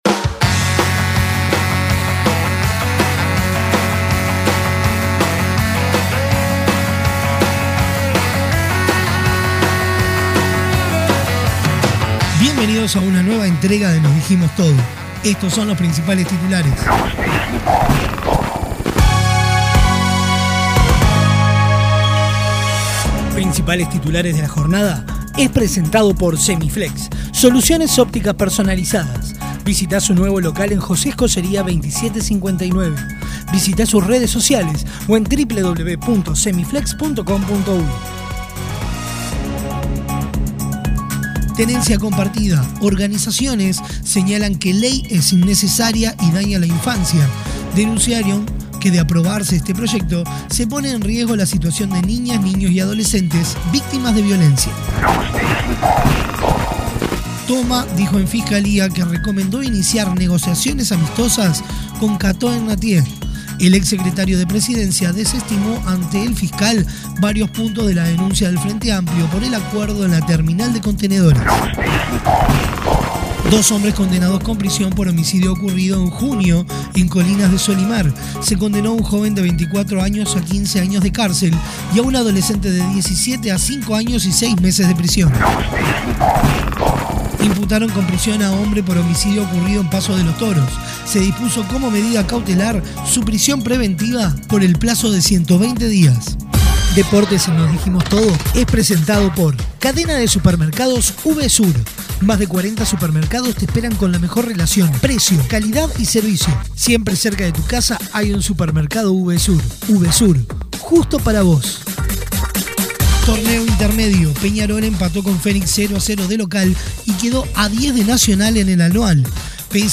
Resumen: Los principales titulares de Uruguay y el mundo acompañados de lo mejor del rock nacional